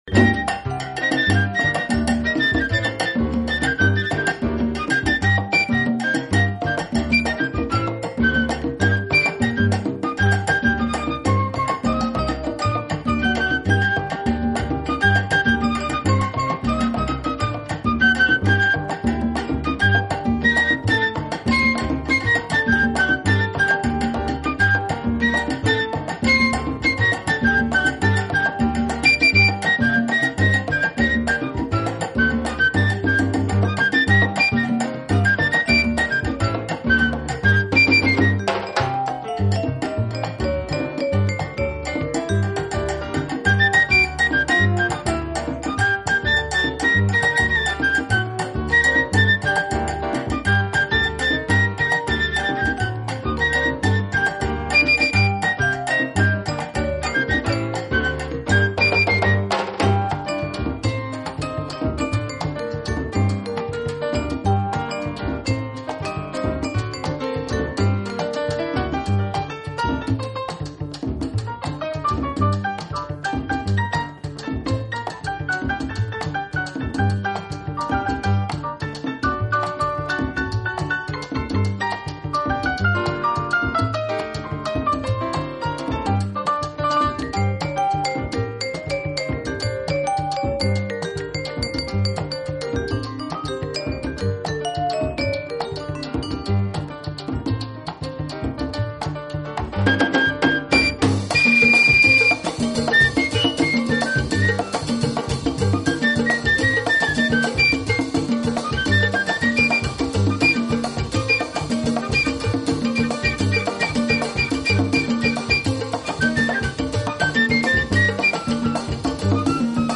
昏暗灯光中，音乐的色彩依然鲜明，曲风摇摆生姿，游走於真实与虚幻